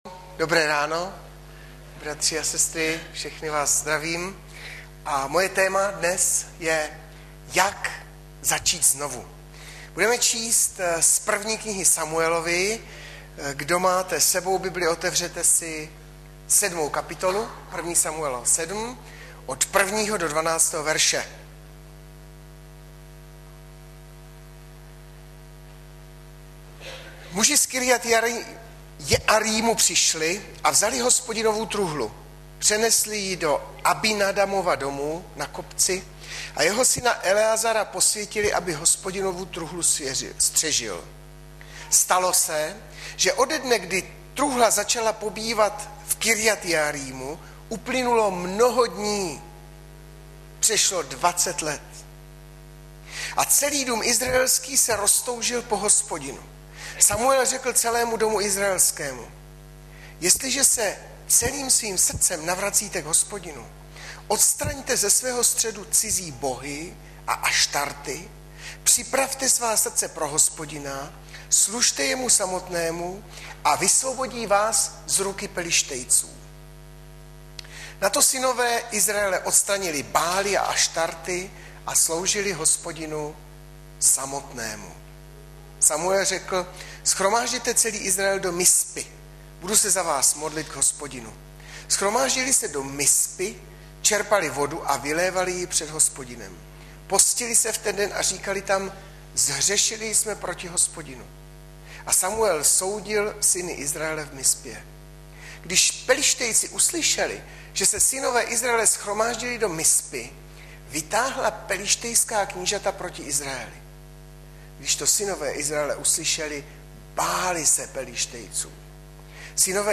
Hlavní nabídka Kázání Chvály Kalendář Knihovna Kontakt Pro přihlášené O nás Partneři Zpravodaj Přihlásit se Zavřít Jméno Heslo Pamatuj si mě  22.01.2012 - ZAČÍT ZNOVU aneb obnova Božího lidu - 1.